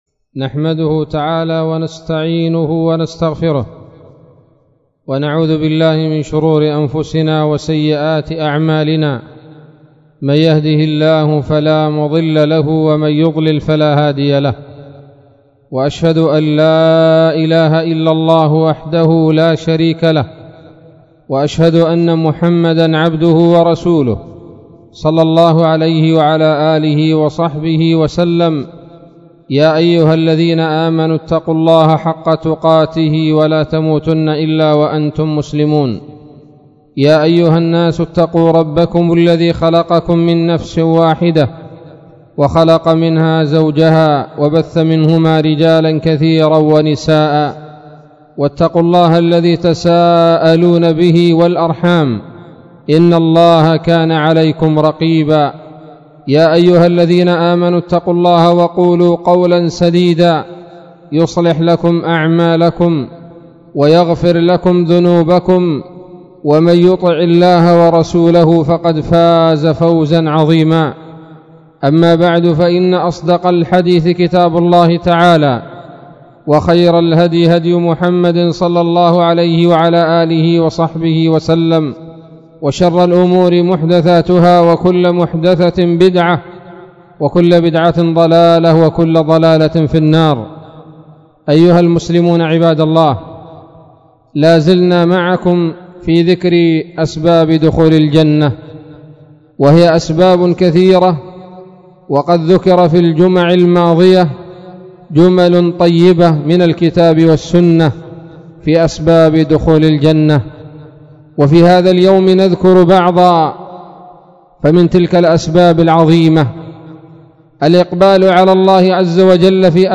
خطبة بعنوان : ((تمام المنة في ذكر بعض أسباب دخول الجنة [3])) 10 ربيع الأول 1438 هـ